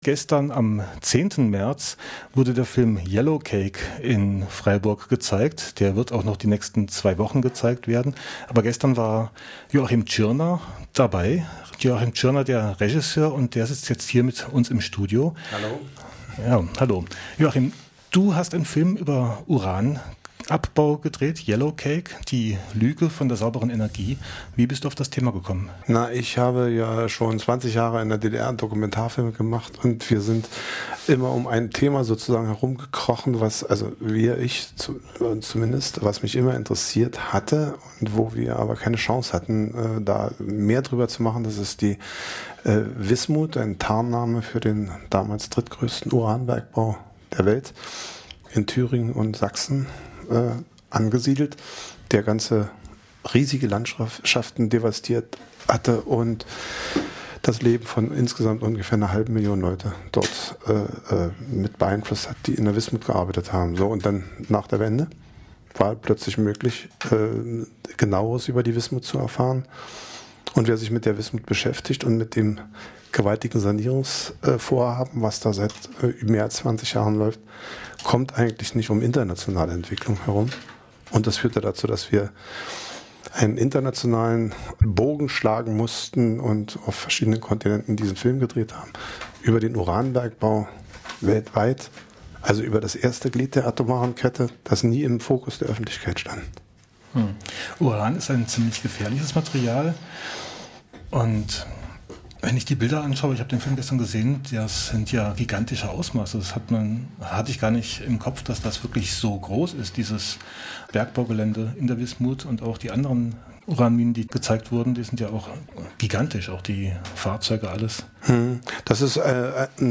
Das RDL-Punkt 12-Mittagsmagazin